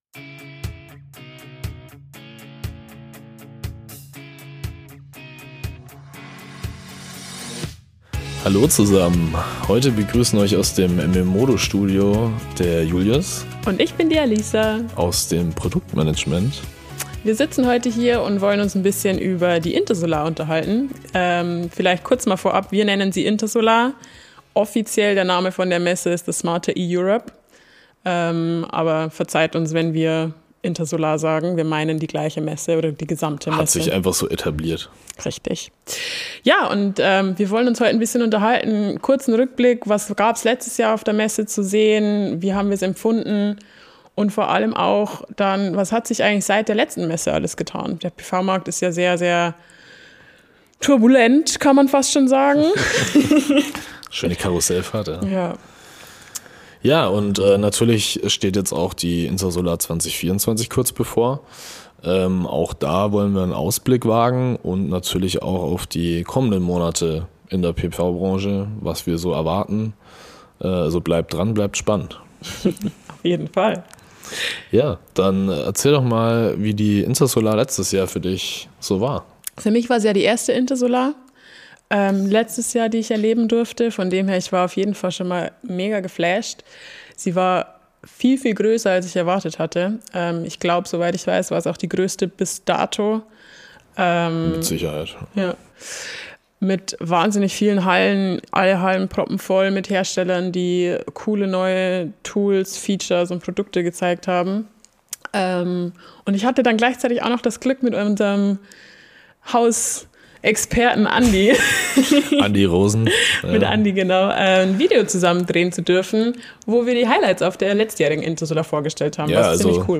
Viel Spaß mit dem spannenden Gespräch der beiden.